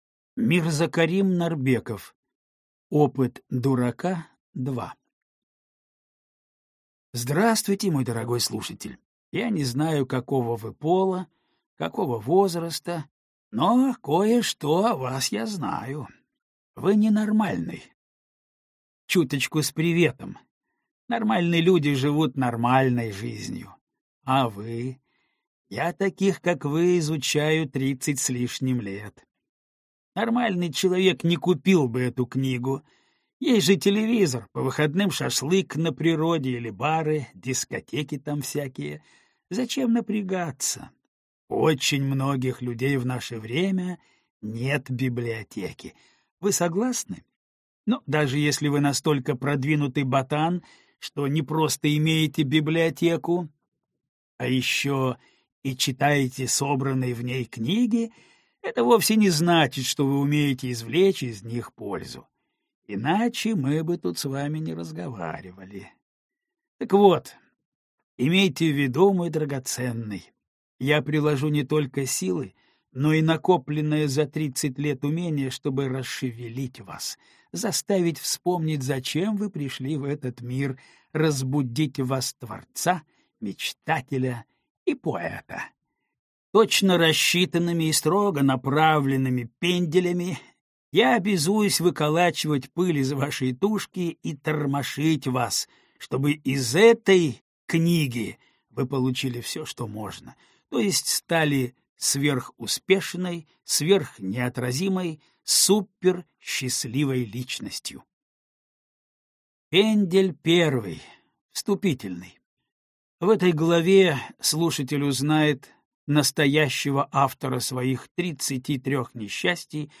Аудиокнига Опыт дурака 2. Ключи к самому себе | Библиотека аудиокниг